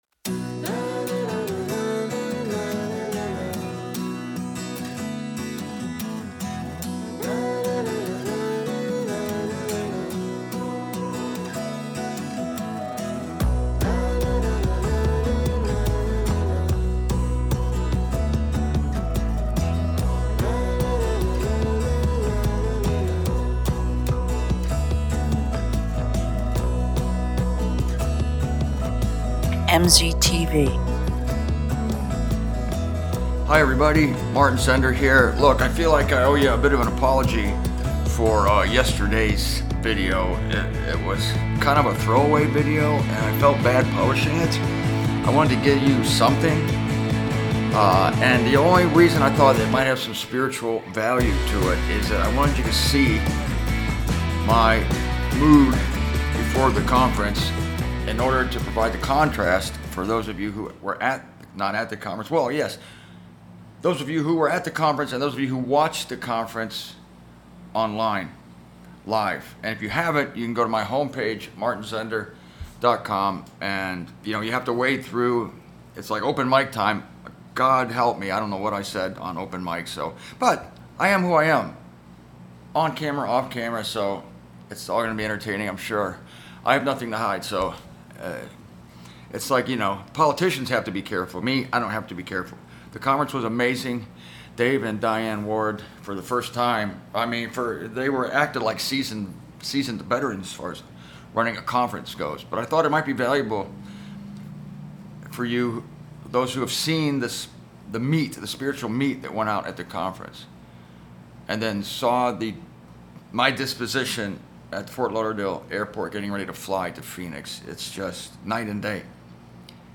The trap of Satan is far more subtle than any of these things. Here is what it is, and here is how it is countered and avoided. Broadcasting from a Best Western hotel in Phoenix, AZ.